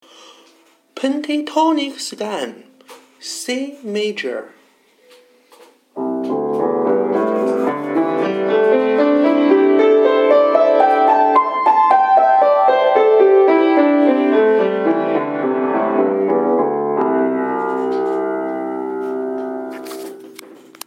Escala pentatónica
Como vedes, soamente empregaremos cinco notas: DO-RE-MI-SOL e LA.
Pentatonic_Scale_C_major.mp3